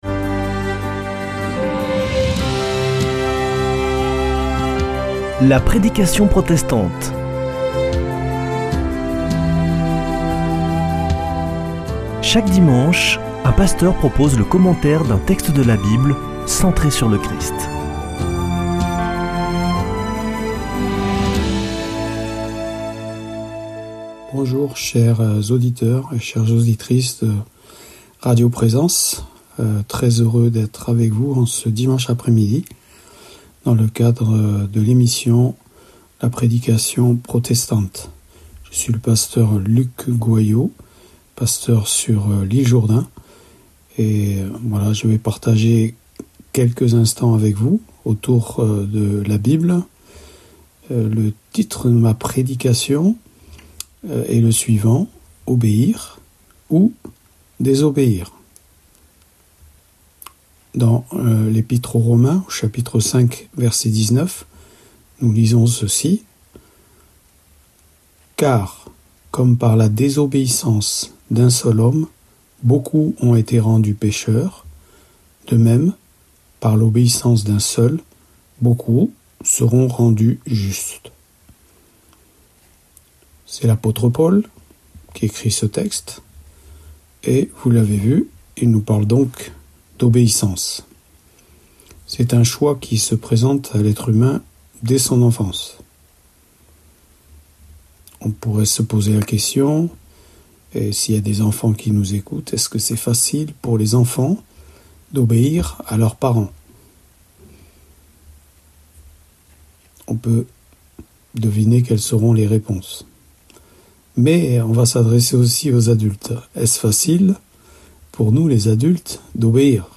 Accueil \ Emissions \ Foi \ Formation \ La prédication protestante \ Obéir ou désobéir ?